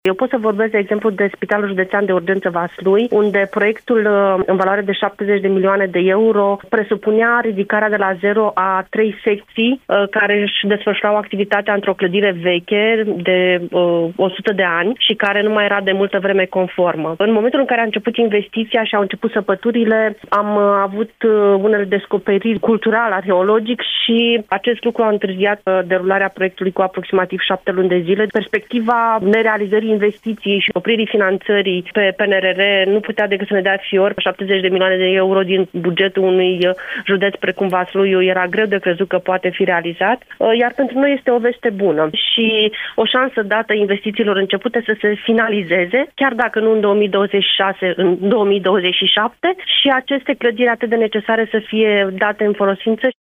Într-o intervenție pentru postul nostru de radio, fostul manager al Spitalului Județean Vaslui, Ana Rinder, actual deputat PSD, a vorbit despre faptul că anunțul de astăzi reprezintă o veste bună, iar investiția va continua pe fonduri europene și nu va afecta bugetele județului: